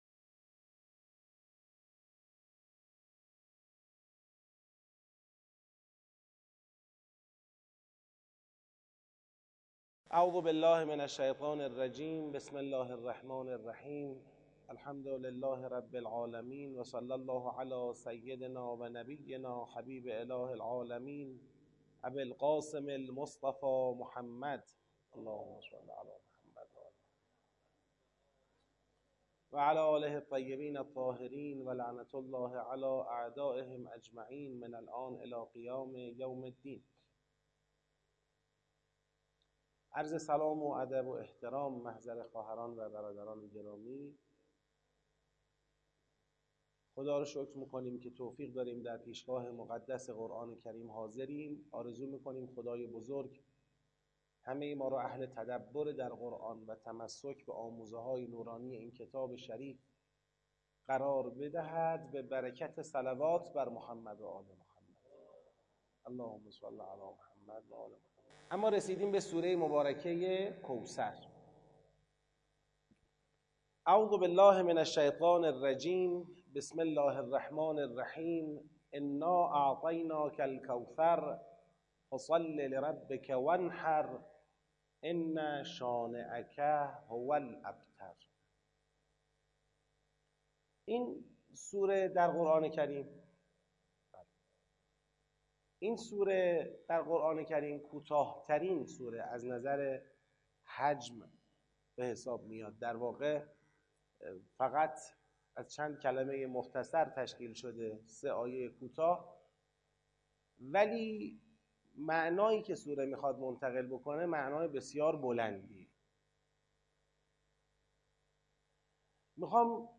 با حضور ۲۵۰ نفر از قرآن آموزان در مسجد پیامبر اعظم (ص) شهرک شهید محلاتی تهران